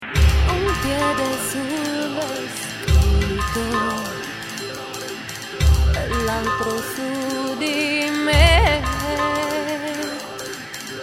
voce e campioni
pianoforte, campionatore e programmazione
batteria, chitarre trattate e campioni
bassi elettronici e contrabasso